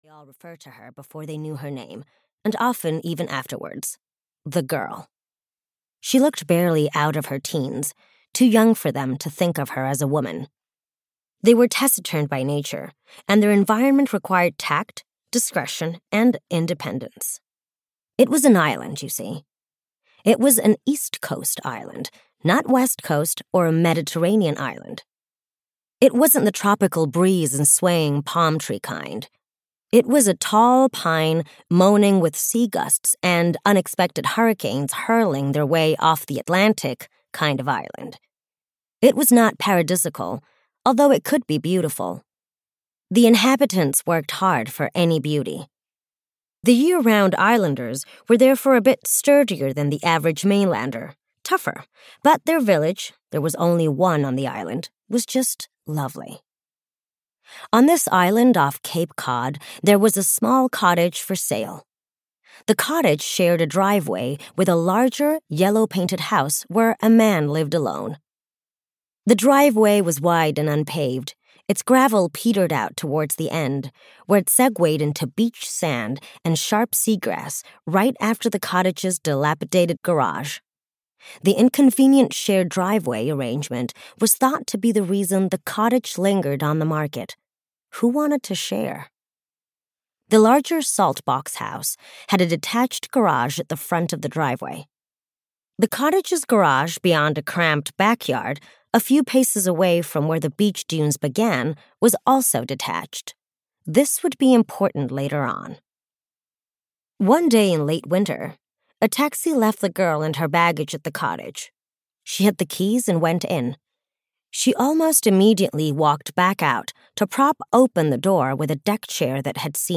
Becoming Delilah (EN) audiokniha
Ukázka z knihy